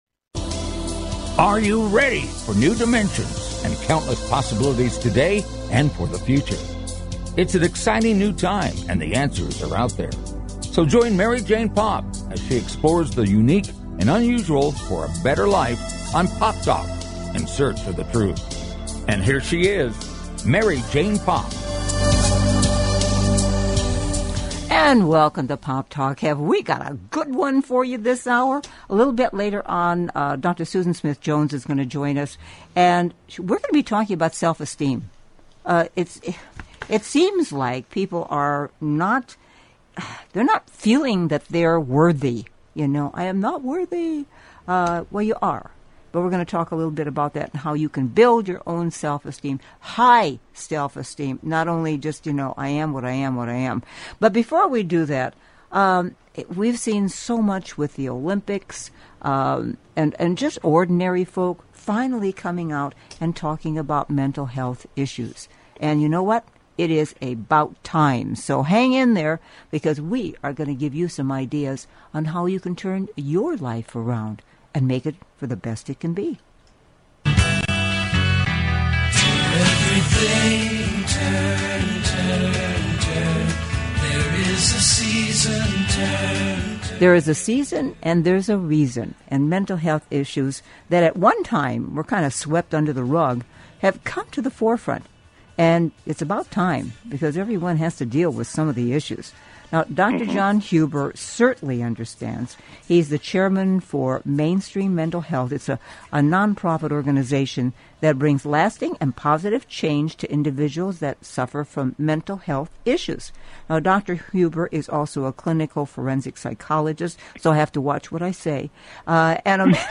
A fast-paced Magazine-style Show dedicated to keeping you on the cutting edge of today's hot button issues. The show is high energy, upbeat and entertaining.